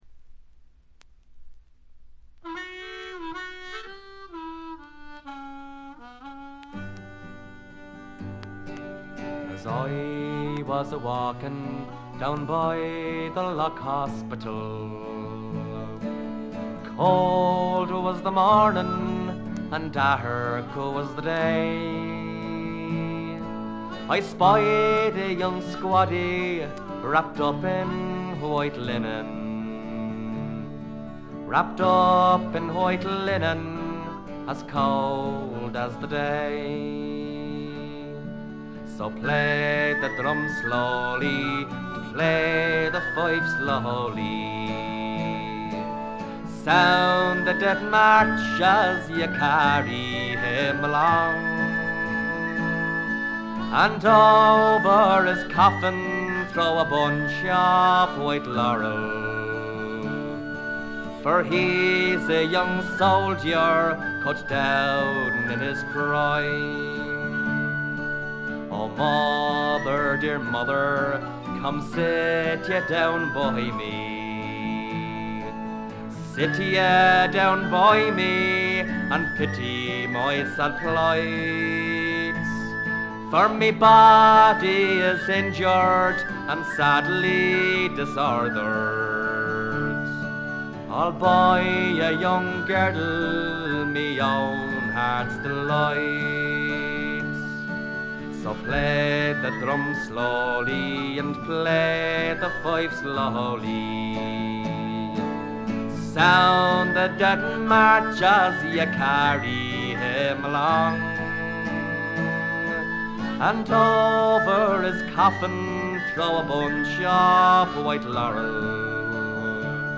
アイリッシュ・フォーク基本中の基本です。
中身は哀切なヴォイスが切々と迫る名盤。
試聴曲は現品からの取り込み音源です。